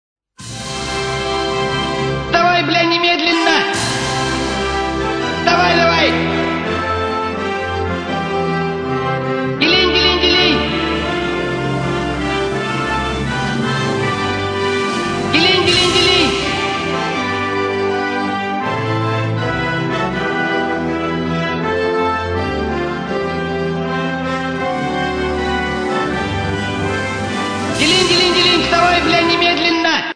Будильник